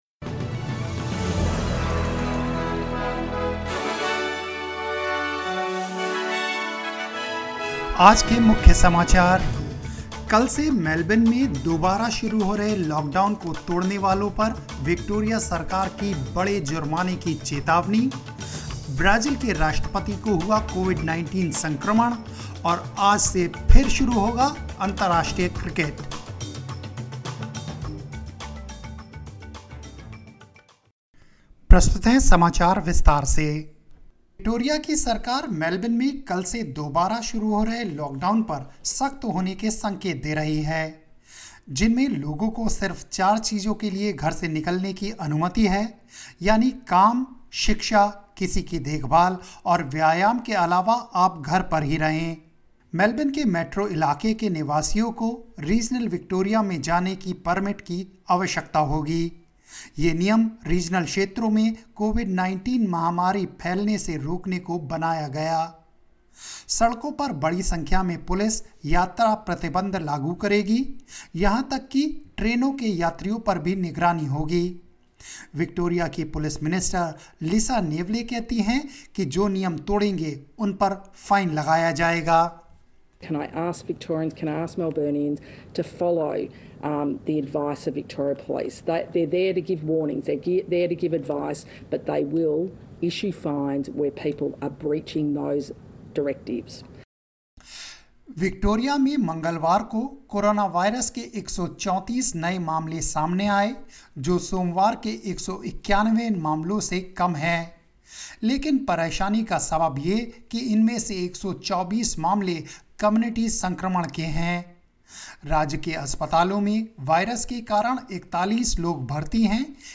hindi_0807_news_and_headlines.mp3